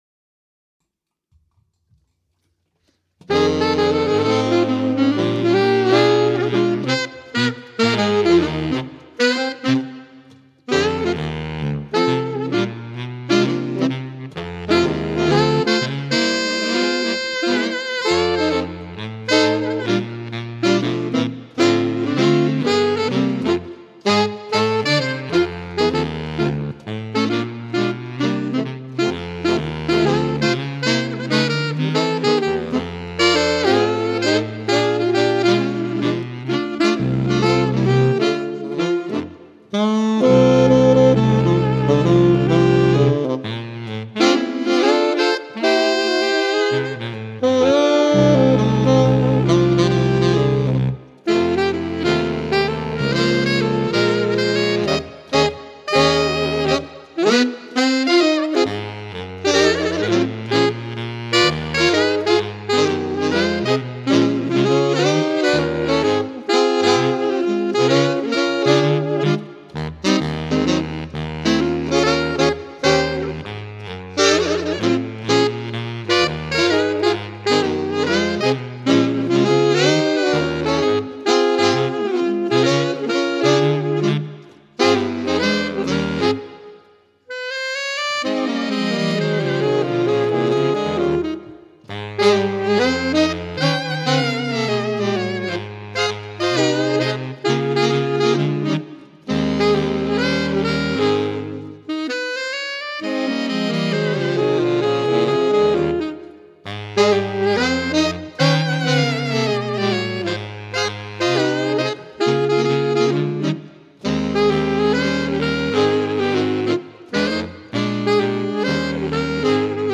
saxophone quartet
Ranges: Alto 1: E3. Tenor: Bb3. Baritone: A1 with ossias